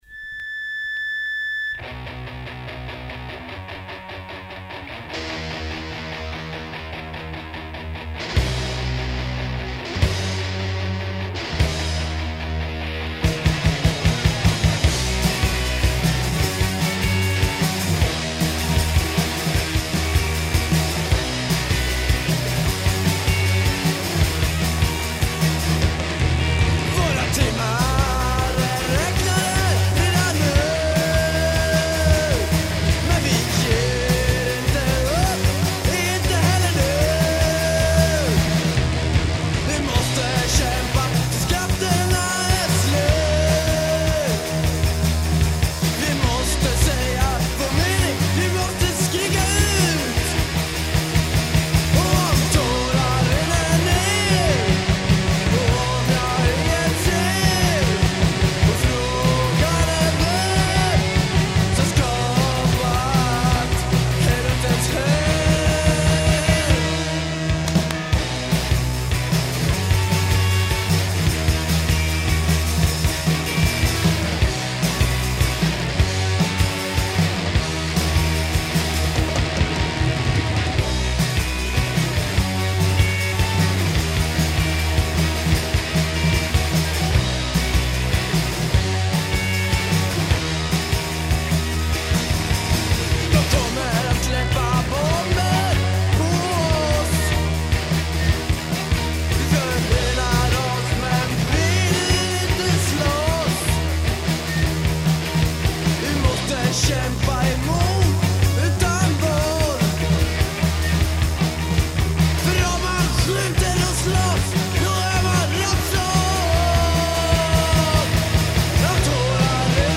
Voice, Guitar
Drums
Bass
punk band